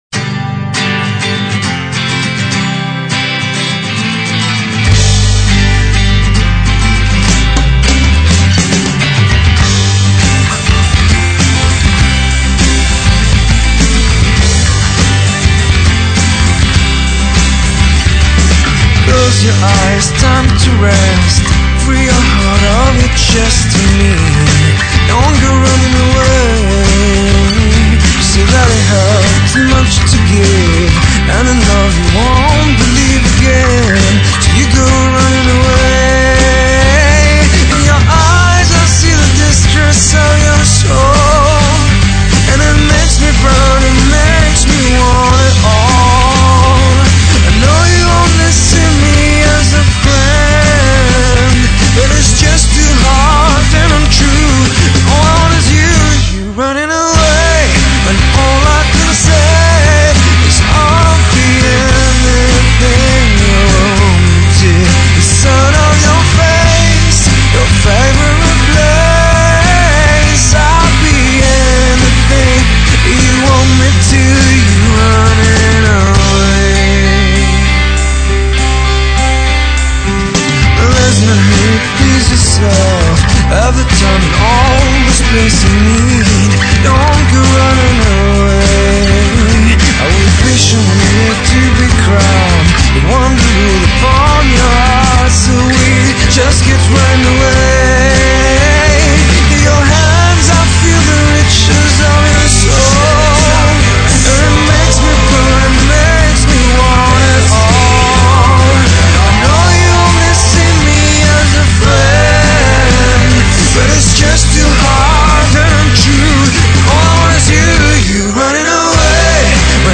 Rock & Roll
Indy